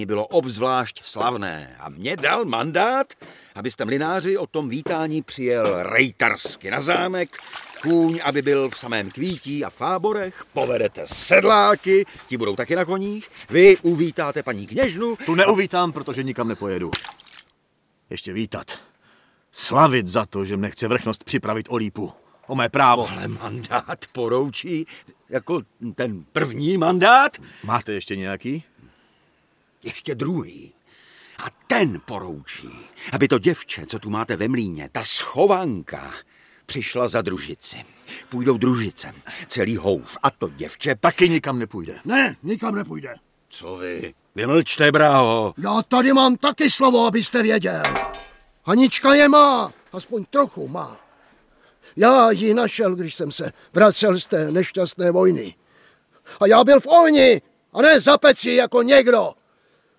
Audiobook
Read: David Novotný